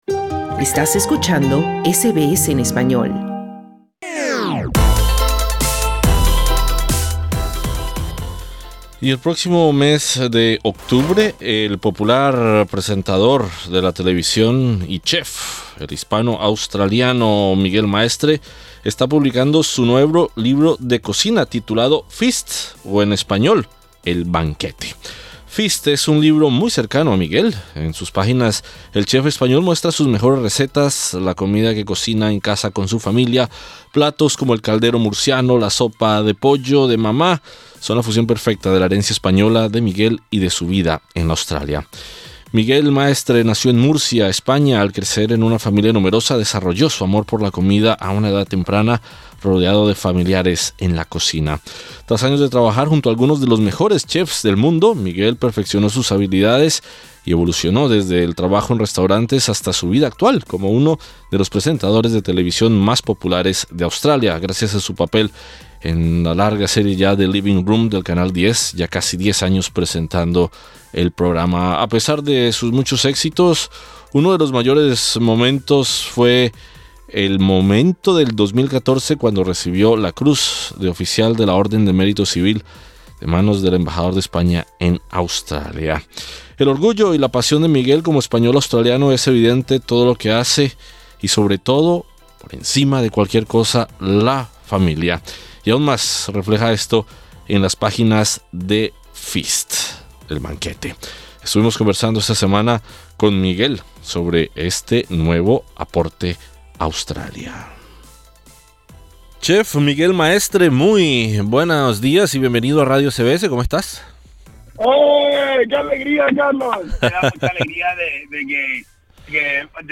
Este es el cuarto libro del reconocido chef y en entrevista con SBS Spanish habla de la importancia de su familia para su éxito en Australia